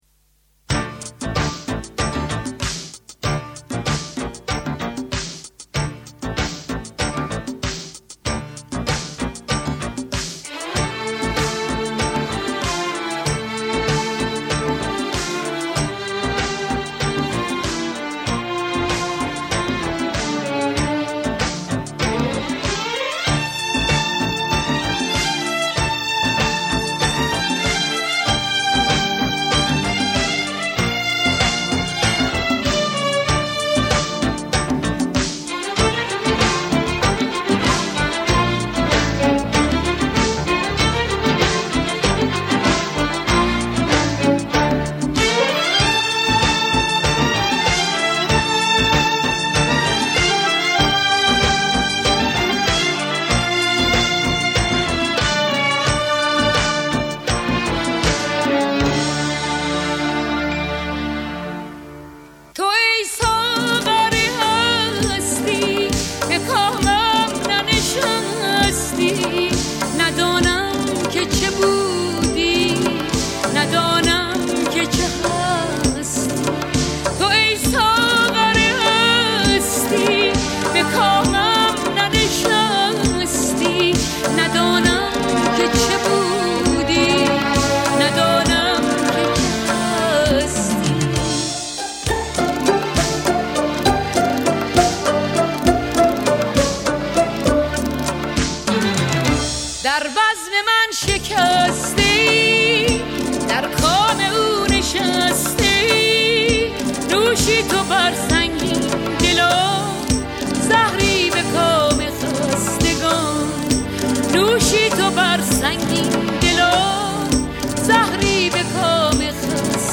پاپ
از برترین خواننده های ایران